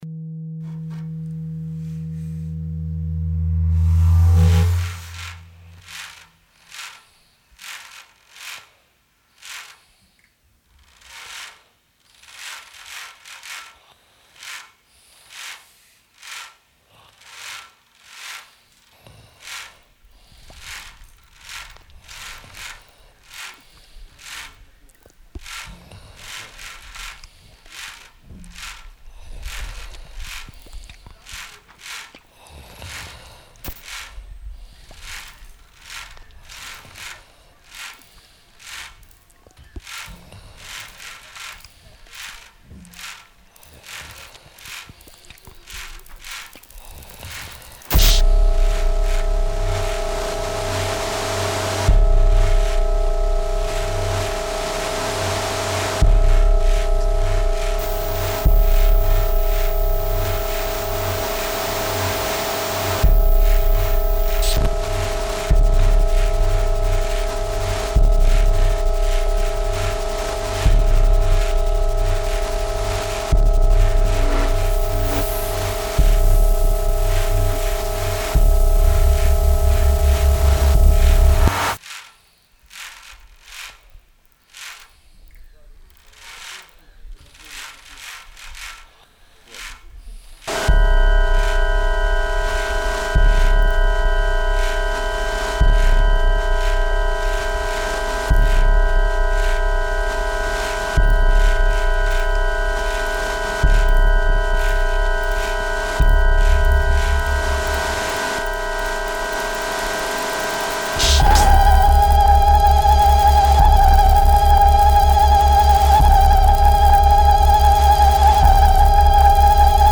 octophonic piece